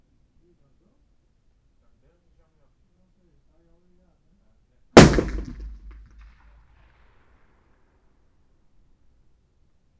01_gunshot/shot556_73_ch01_180718_163449_03_.wav · UrbanSounds/UrbanSoundsNew at 76bfaa6c9dcc58084f9109a20dd5a56c091ddcfb
UrbanSounds
Environmental
Streetsounds